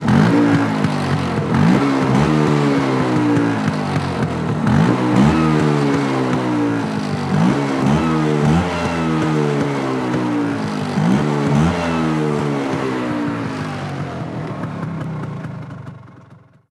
Moto marca Vespa acelerando
motocicleta
Sonidos: Transportes